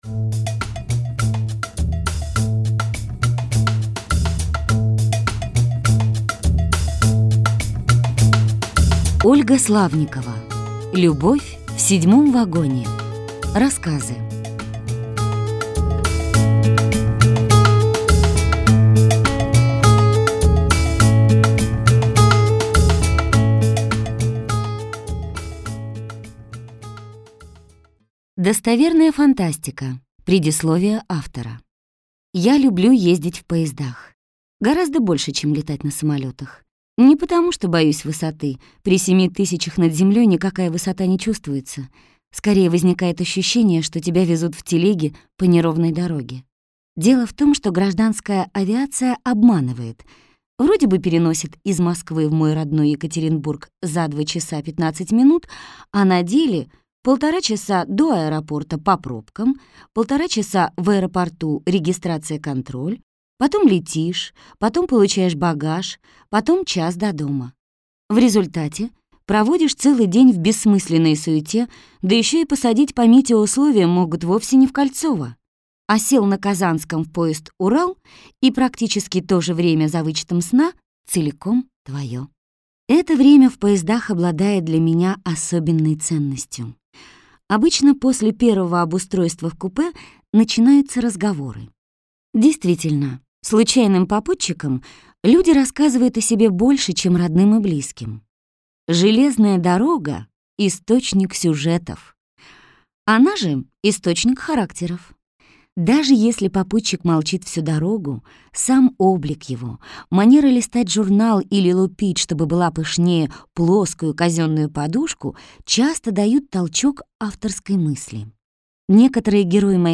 Аудиокнига Любовь в седьмом вагоне | Библиотека аудиокниг